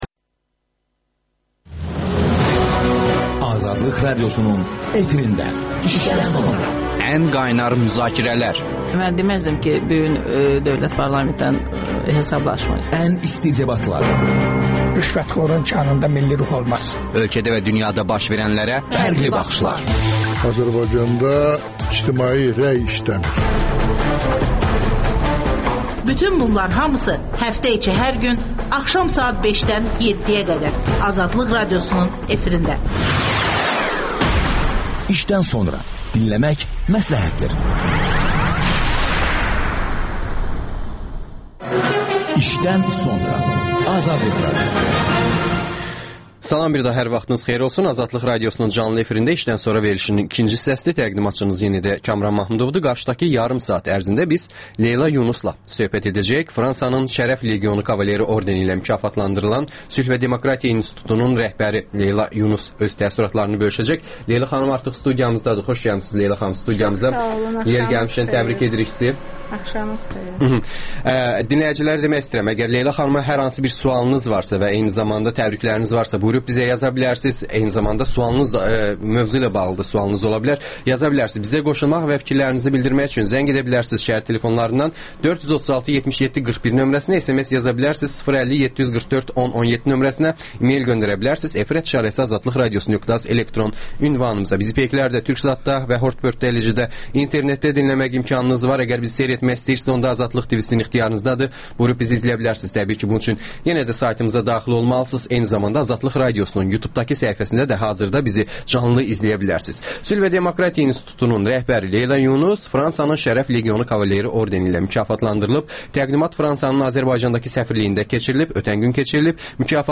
İşdən sonra - Leyla Yunusla söhbət